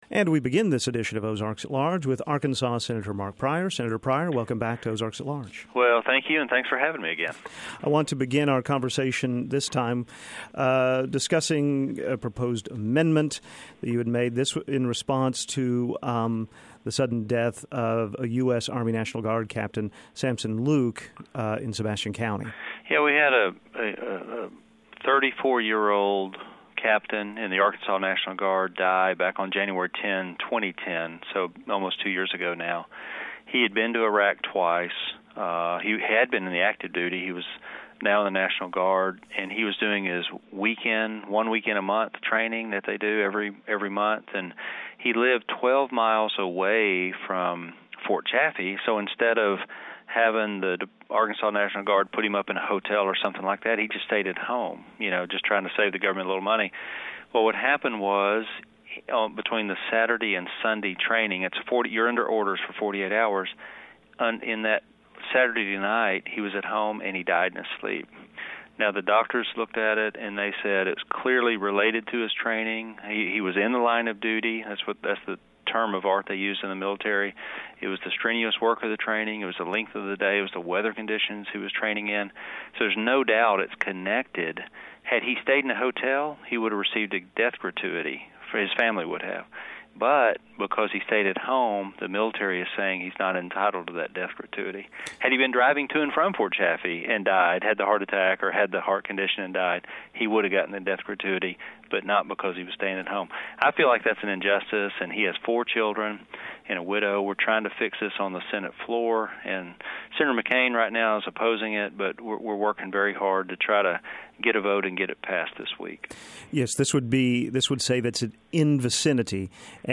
A Conversation with Pryor